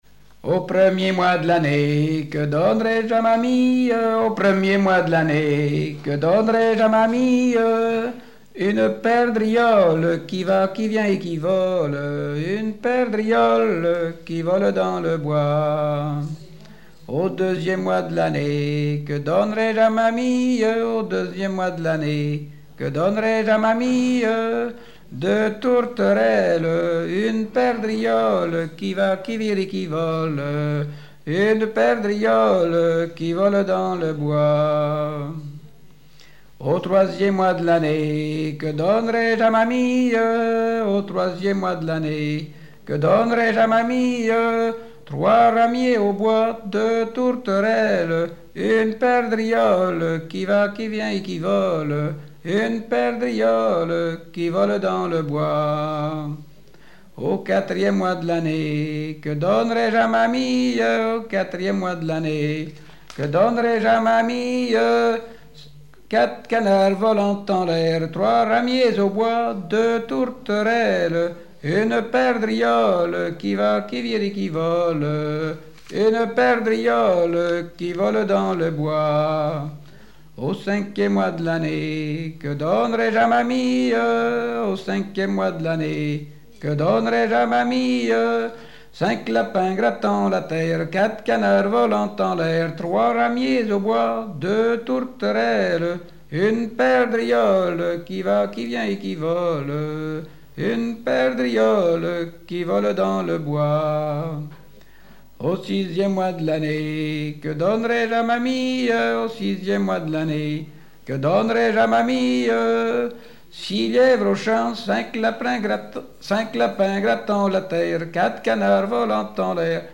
Genre énumérative
Répertoire de chansons traditionnelles et populaires
Pièce musicale inédite